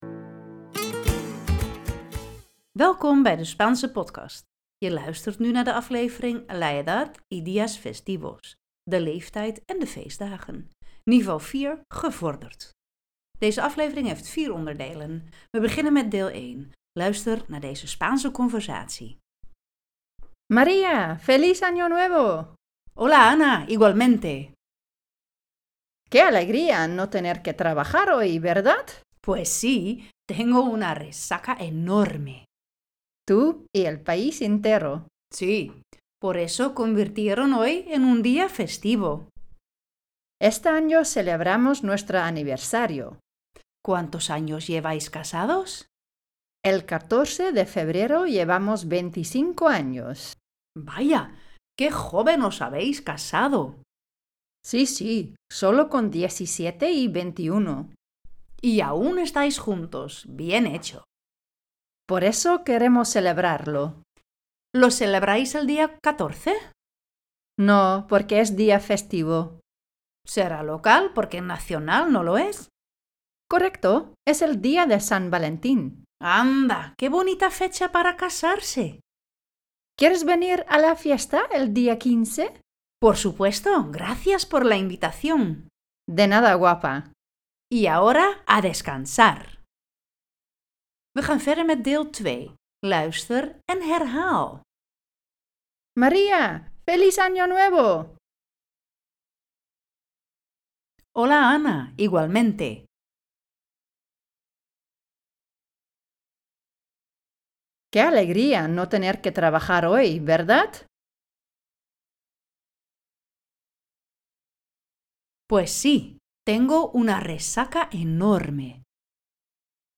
La edad y los días festivos Niveau 4 - Gevorderd. Deze aflevering heeft vier onderdelen: - In deel 1 luister je naar de Spaanse conversatie - In deel 2 luister je nogmaals naar de Spaanse zinnen en herhaal je deze zelf - In deel 3 luister je naar de Nederlandse zin, daarna luister je de Spaanse zin die je vervolgens herhaalt - In deel 4 hoor je de Nederlandse zin en zeg je zelf de zin hardop in het Spaans.